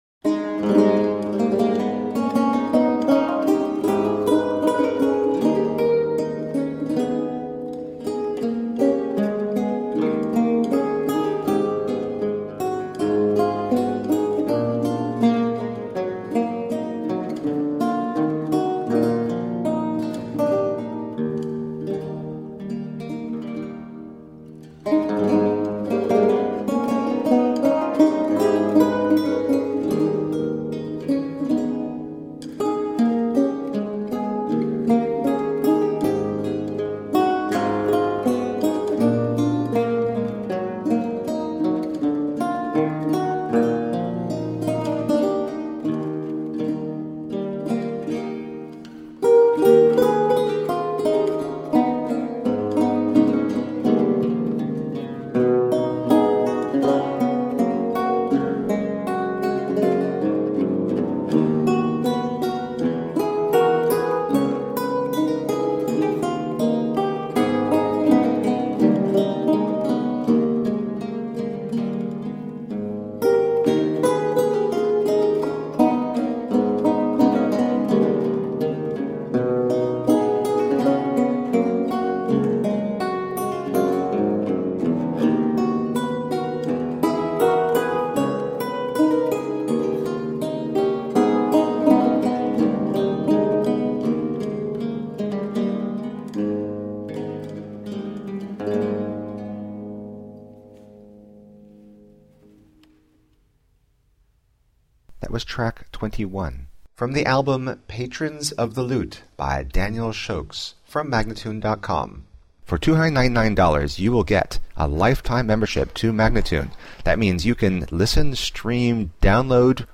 A feast of baroque lute.
Classical, Baroque, Instrumental